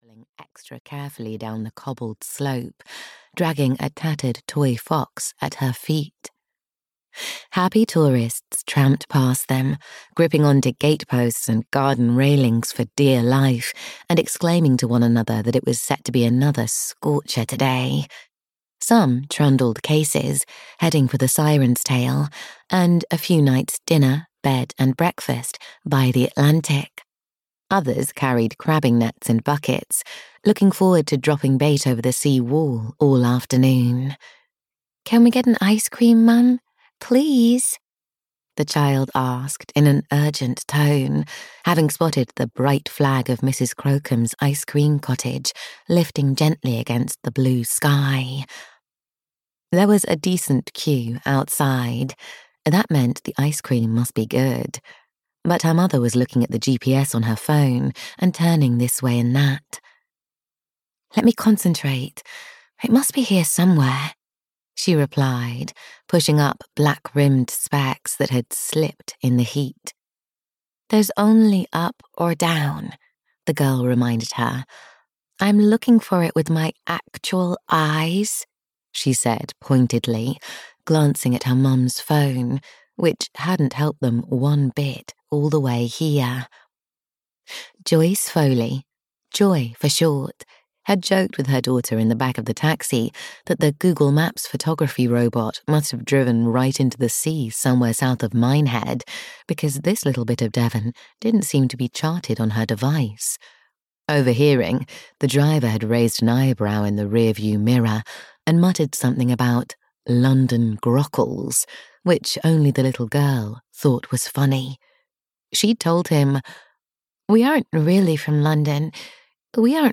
Something New at the Borrow a Bookshop (EN) audiokniha
Ukázka z knihy